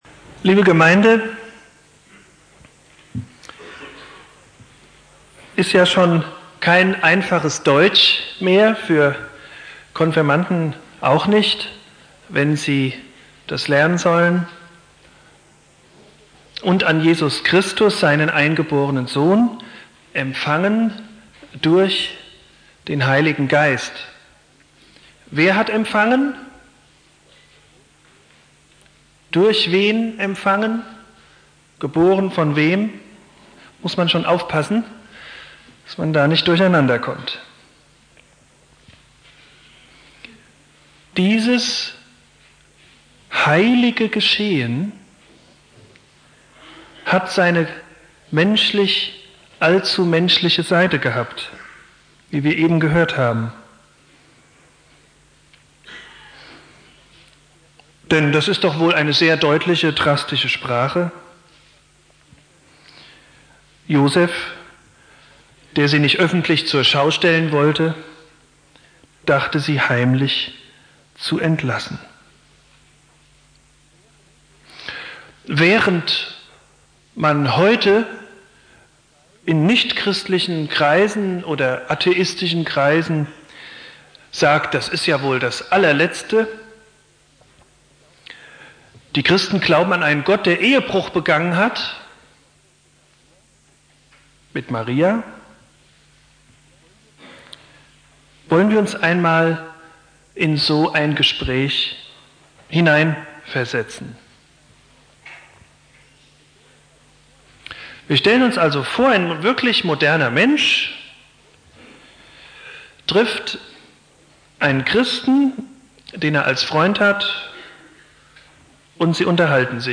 Predigt
2.Weihnachtstag Prediger